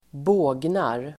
Ladda ner uttalet
Uttal: [²b'å:gnar]